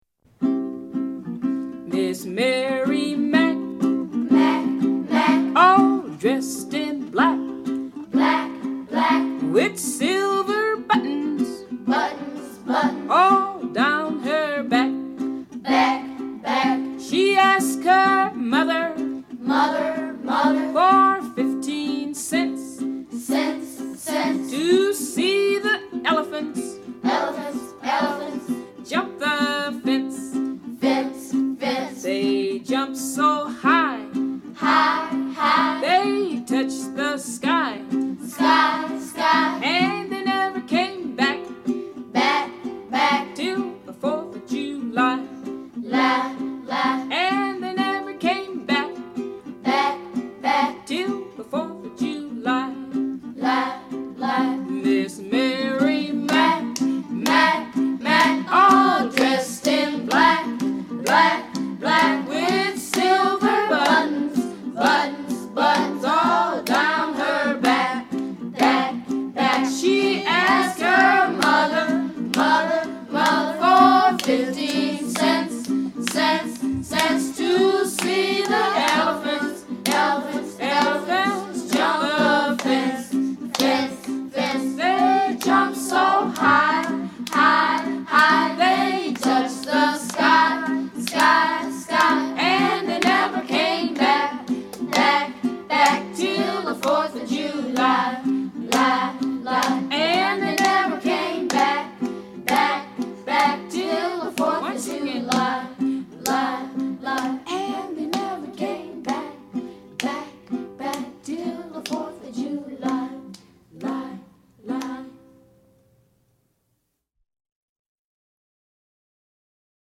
Traditional song “Miss Mary Mack” performed by Ella Jenkins from the album, You’ll Sing a Song and I’ll Sing a Song, used courtesy of Smithsonian Folkways.